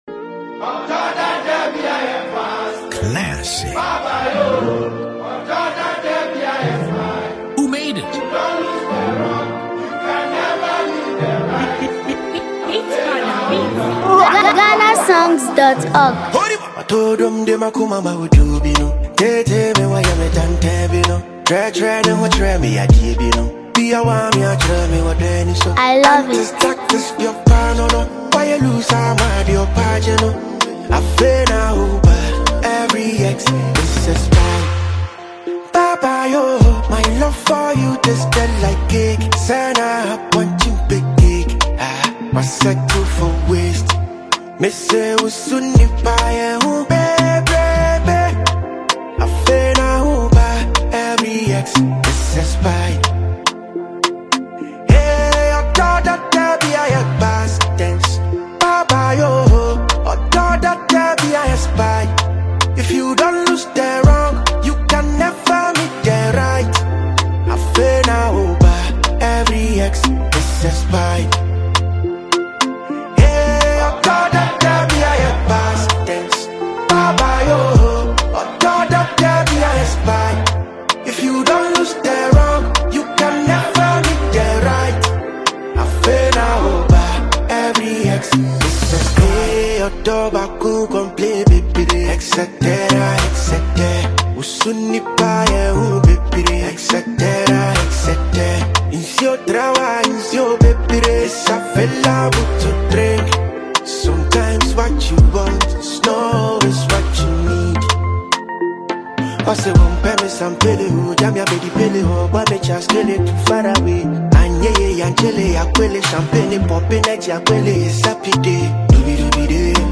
Ghanaian singer and songwriter
With a catchy hook and upbeat Afrobeat rhythm